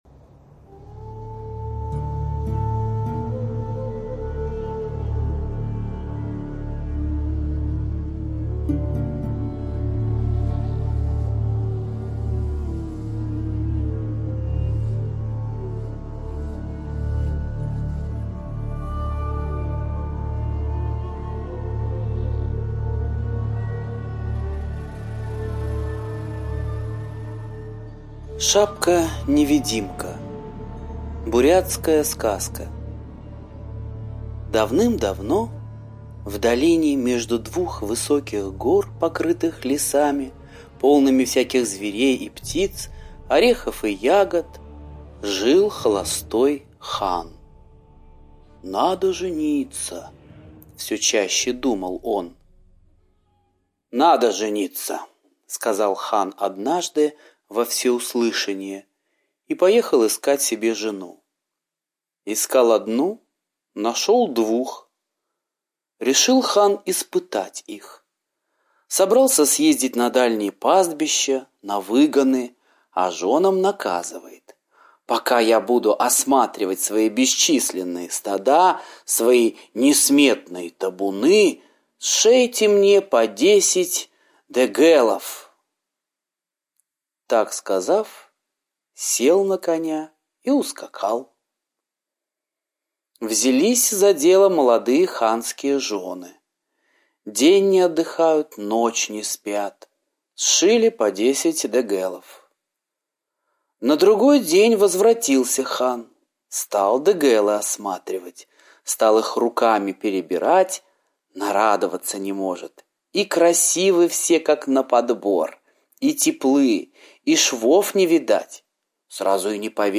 Шапка-невидимка - восточная аудиосказка - слушать онлайн